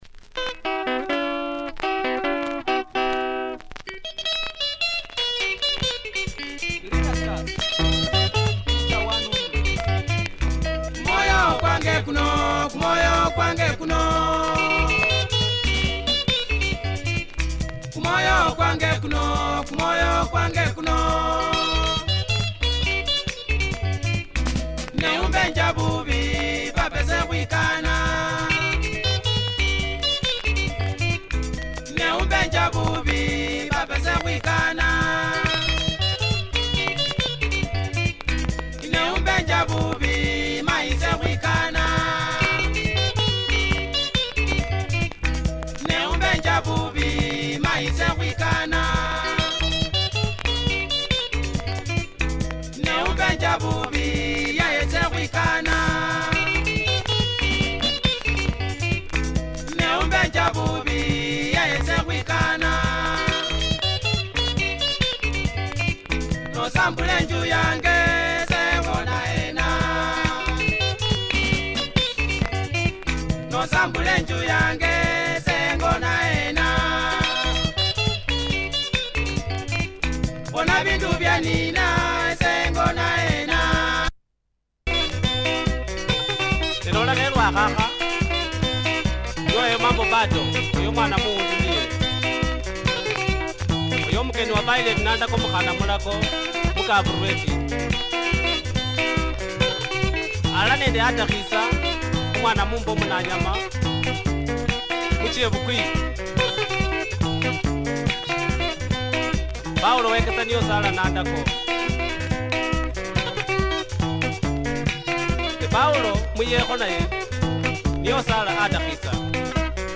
Good bukusu benga, nice vibe, check audio! https